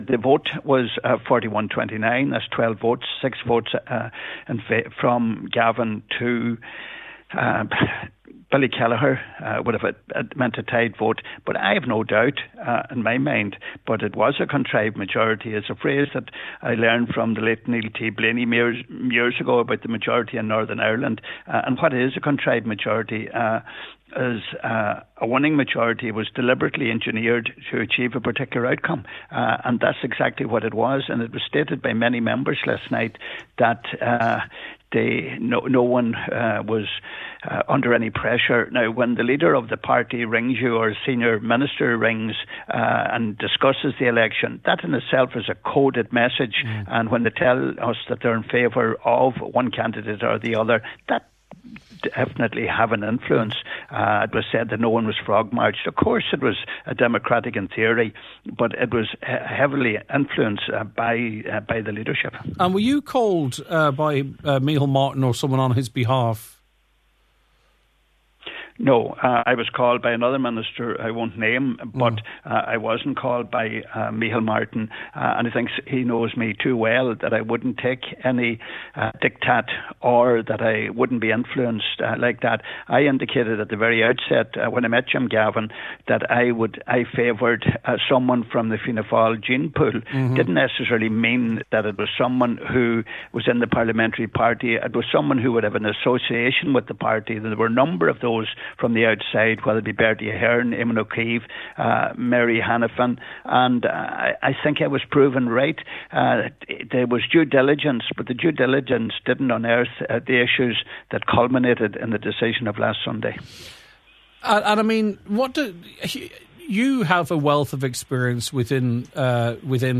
On today’s Nine til Noon Show, Deputy Gallagher said a group is to be established within the party to review all aspects of nomination, and it should report back shortly…….